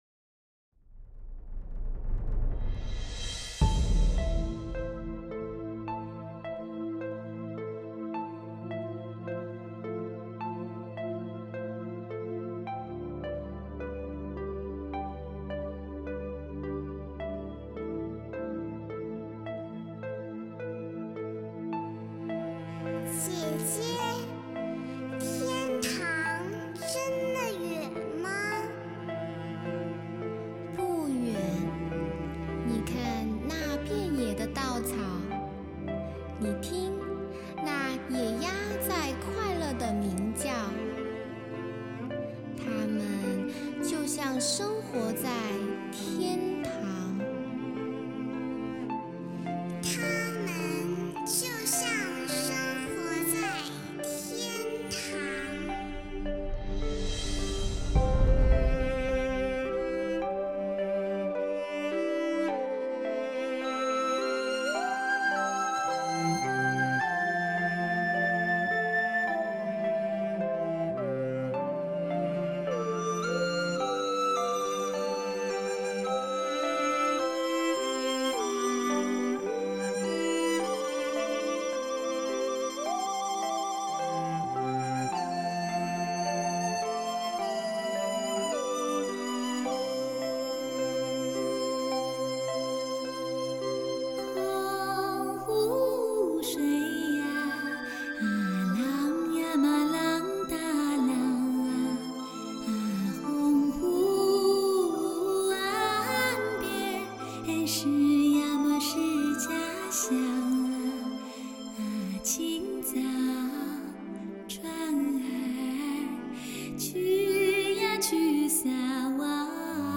高音女皇后
多声道环绕，营造真正立体三维空间感,让您在家也能充分感受广阔无边、无与伦比的震撼体验。
鉴赏级音响系统动效展示，教科书般的环绕制作，乐器定位精准极致，声场宏大环绕效果显著。